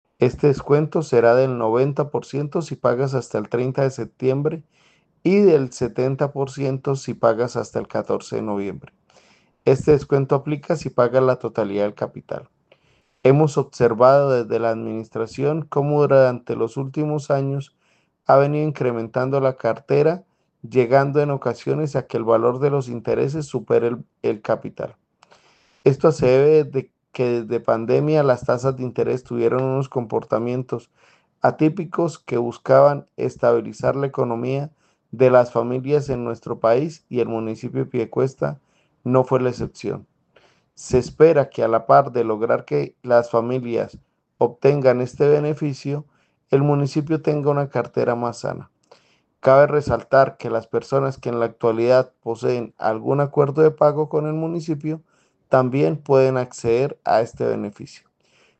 Jhonattan Siza Bastilla, secretario de Hacienda de Piedecuesta